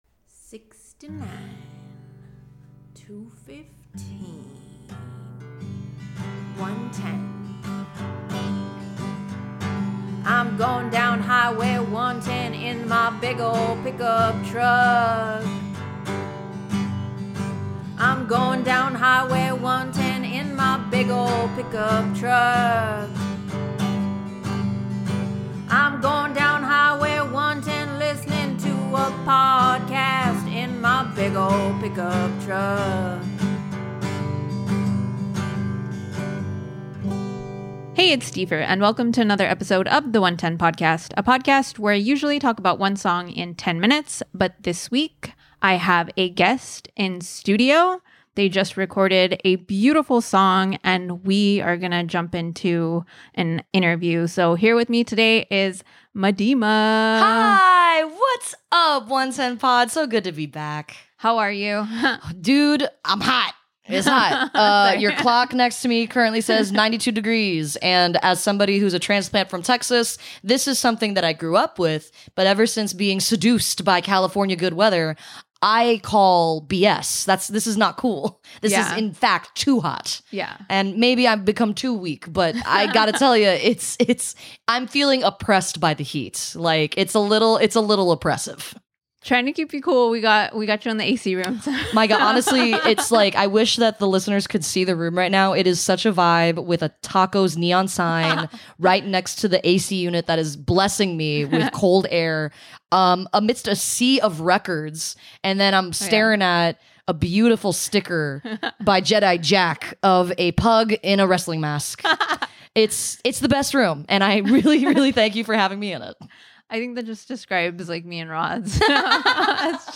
This acoustic take captures the heart of the Stripped Down series, offering a simpler, but no less beautiful, contrast to the album version. Beyond the music, we dive into the realities of releasing a record as an independent artist—from balancing creativity with logistics to collaborating with other talented musicians.